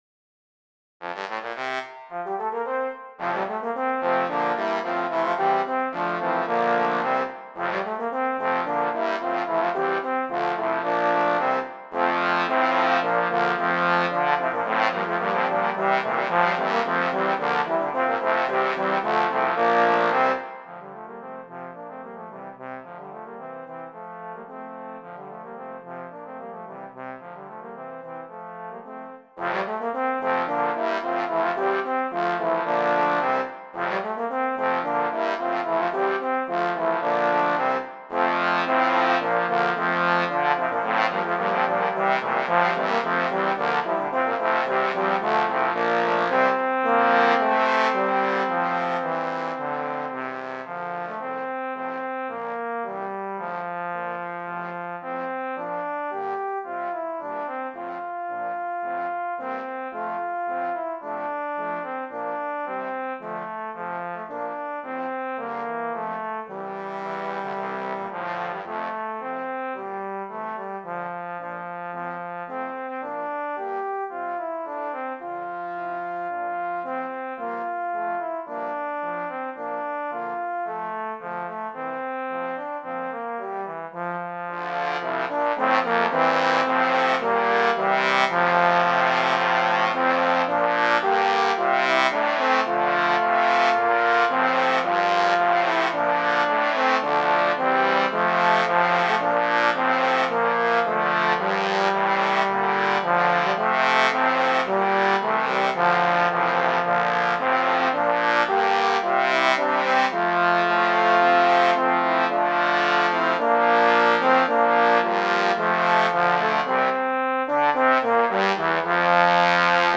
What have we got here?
Voicing: Trombone Sextet